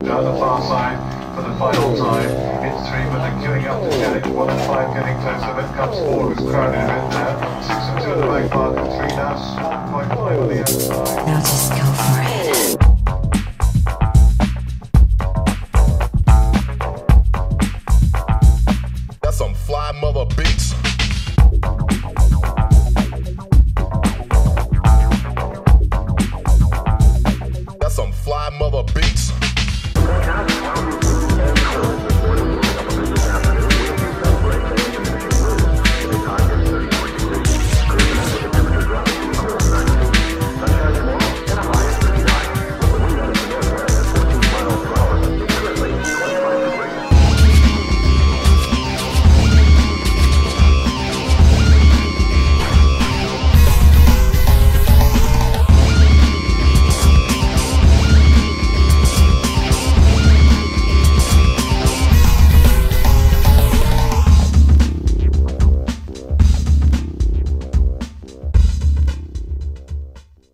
BPM112
Audio QualityLine Out